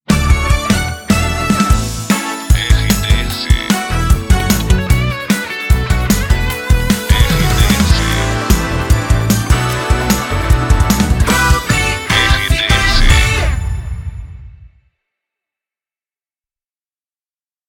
Volta de Bloco Longa